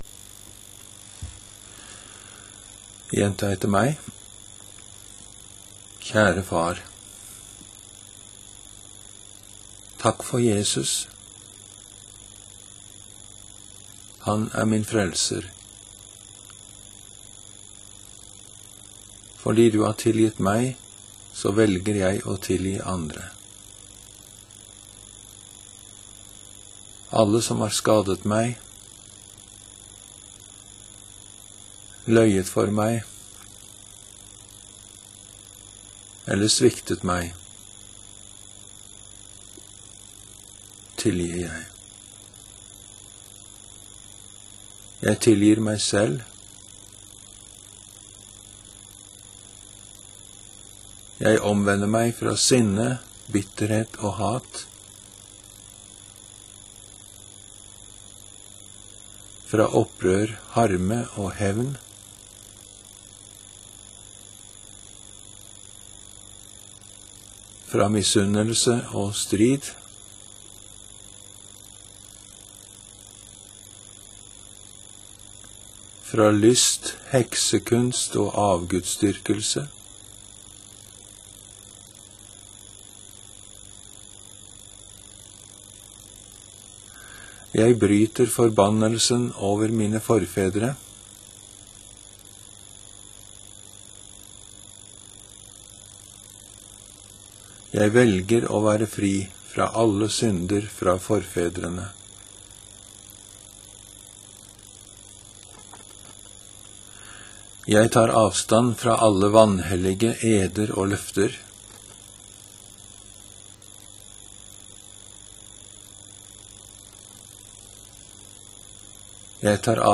En utfrielsesbønn
Gjenta etter meg den første delen av utfrielsen. Deretter vil jeg binde demonene og befale dem å forlate deg. Til slutt vil jeg be om Gud`s velsignelse over ditt liv.